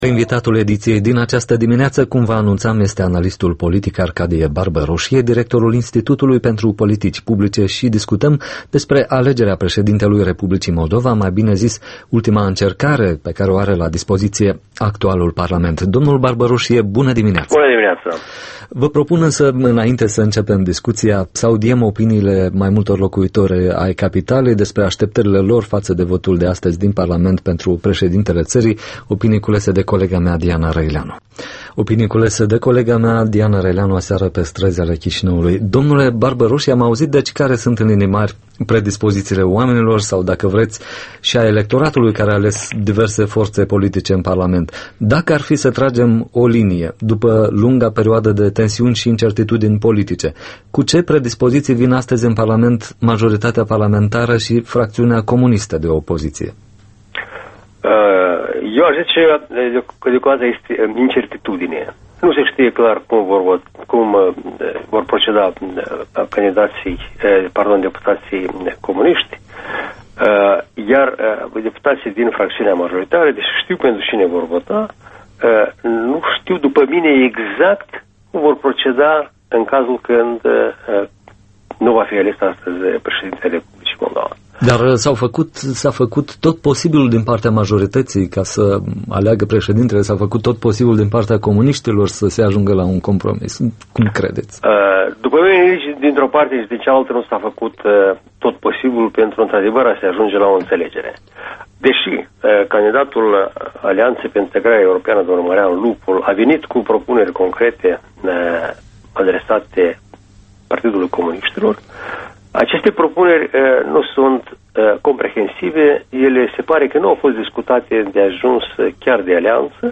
Interviul matinal